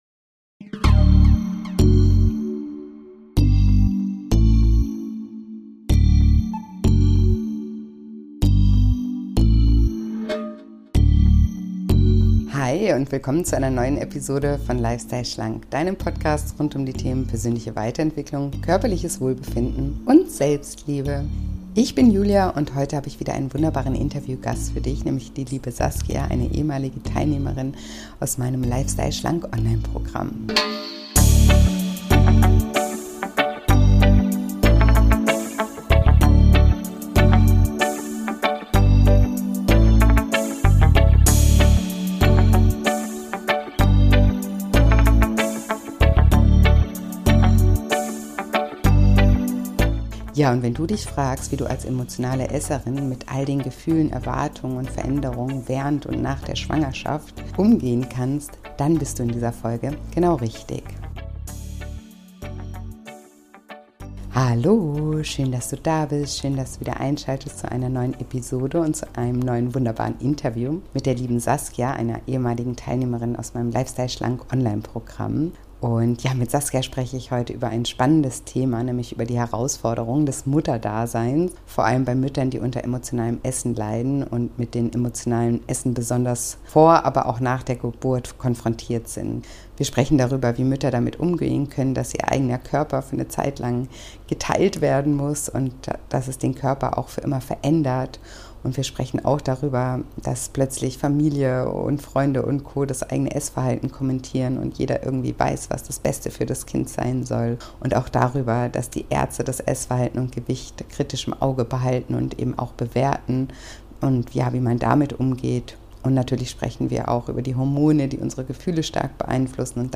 In diesem Interview beleuchten wir insbesondere die Herausforderungen, mit denen Mütter, die unter emotionalem Essen leiden, vor und nach der Geburt konfrontiert sind.